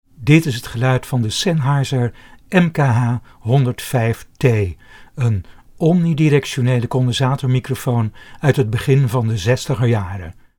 Het resultaat is een zuiver signaal, met weinig vervorming, door dat signaal vervolgens elektronisch aan te passen wordt een heel 'rechte' opnamekarakteristiek bereikt, waarbij ook de lage tonen heel goed worden weergegeven.
Sennheiser MKH 105T Omni